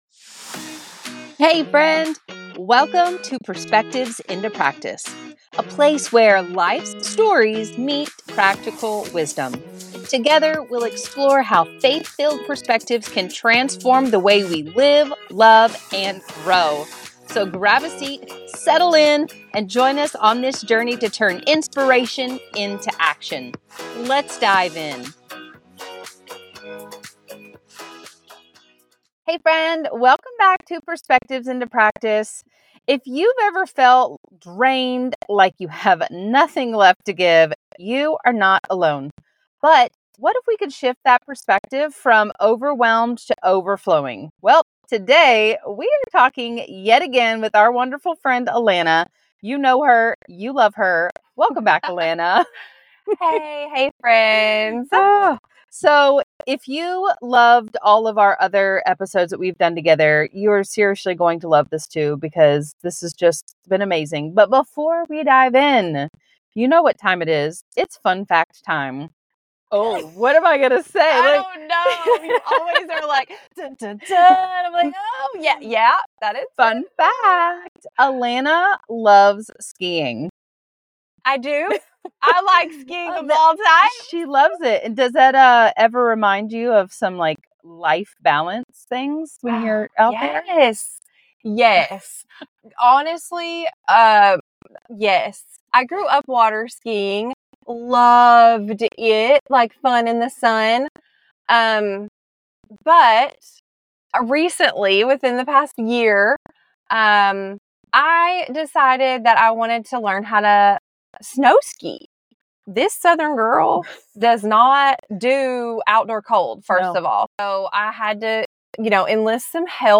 If you feel overwhelmed and like you have nothing left to give, this conversation is for you.